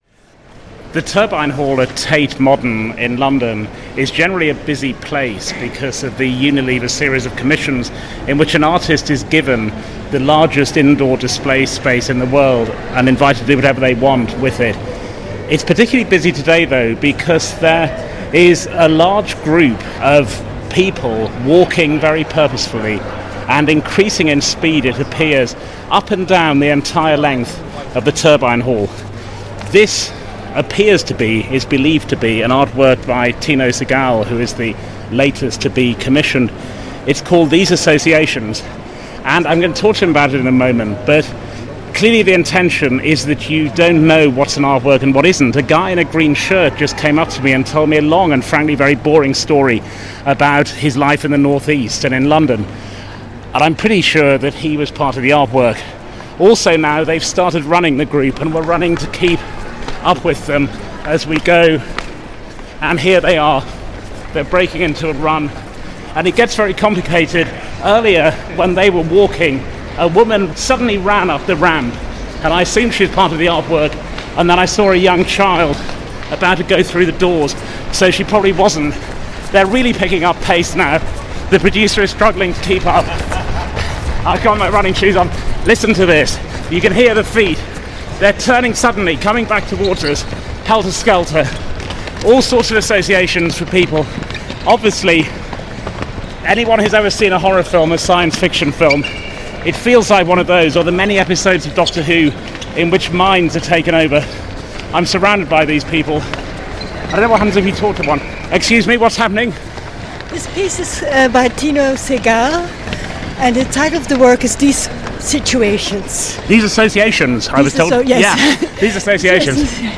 Front Row’s Mark Lawson limbers up as he reports from the latest commission to be created for the vast Turbine Hall at Tate Modern - ‘These Associations’ by Tino Sehgal, an artwork which blurs the lines between reality and fiction.